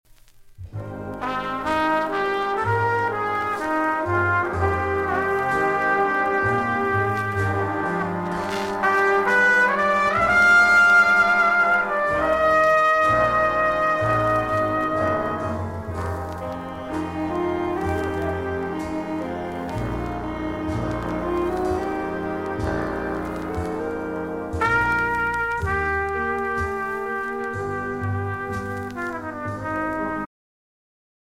January 7, 1972 marked its 22nd concert.
trumpet
trombone
bass trombone
alto saxophone
tenor saxophone
baritone saxophone
piano
guitar
Jazz -- 1971-1980